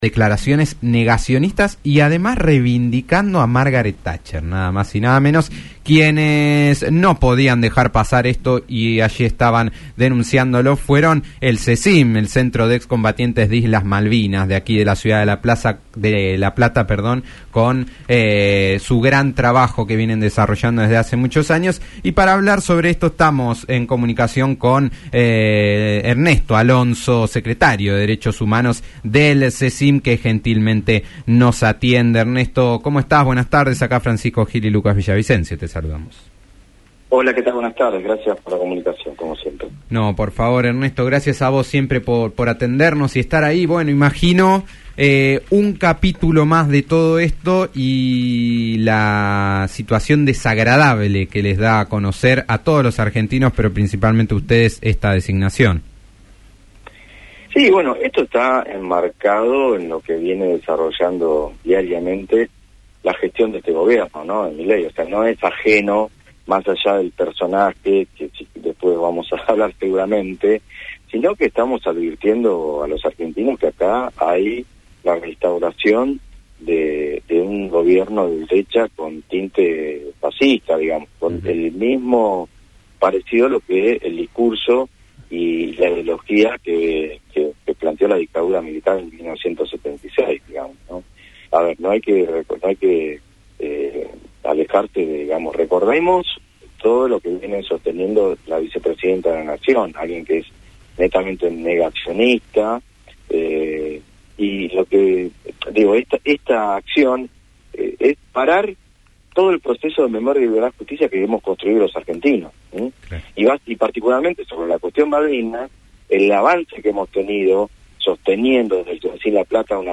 en diálogo con Después del Mediodía (fm 90.9)